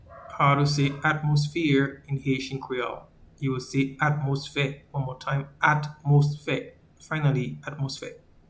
Pronunciation:
Atmosphere-in-Haitian-Creole-Atmosfe.mp3